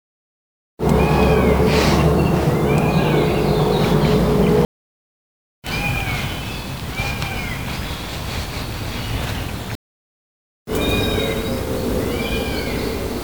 Bird Call?
It reminded me of a peacock as well but it seems unlikely as I was in a small area of woodland in South London -- it's also possible the sound was coming from someone's garden as I couldn't pinpoint the location. It's certainly a similar intonation but not sure "my" sound is quite as full-bodied and throaty as a peacock call...It crossed my mind that it was a fox but it doesn't seem to be a "typical" fox call either...although foxes apparently have a very wide vocabulary of calls so it could be?...To my ear it is very "bird-like"...Any ideas?